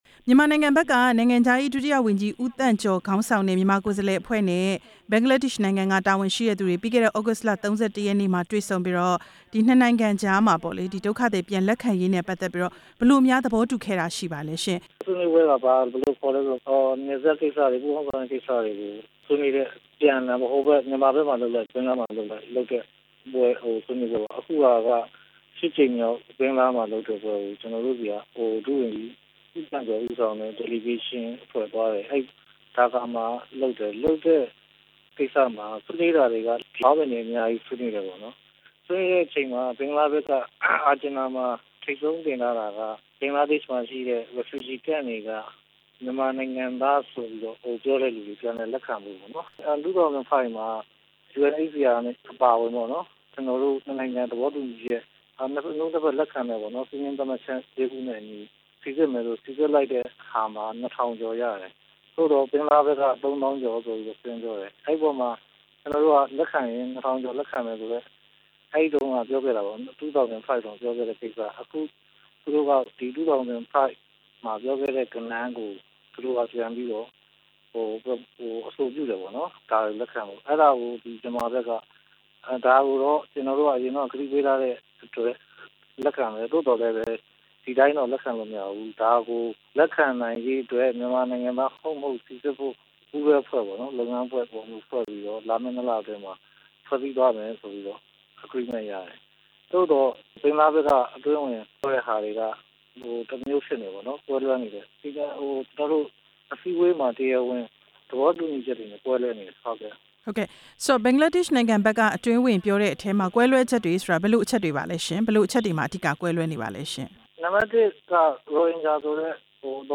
သမ္မတရုံး အကြီးတန်းအရာရှိနဲ့ မေးမြန်းချက်